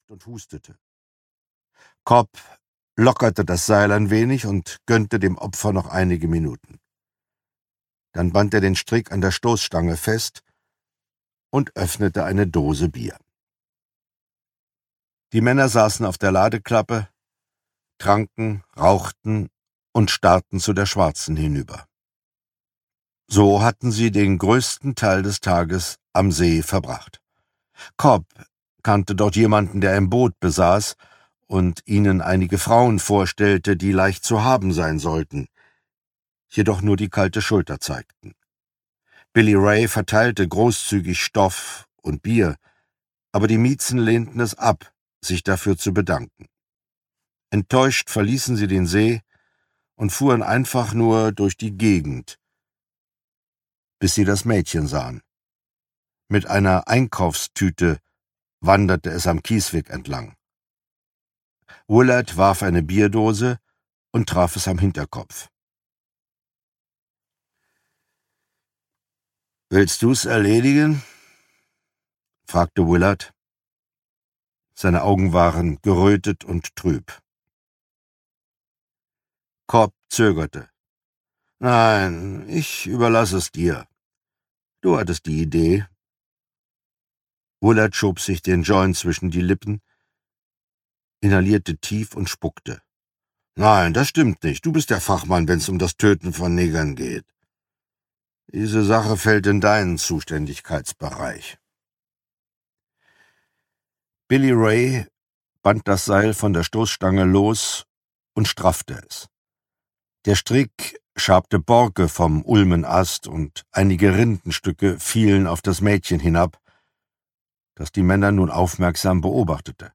Die Jury (DE) audiokniha
Ukázka z knihy
• InterpretCharles Brauer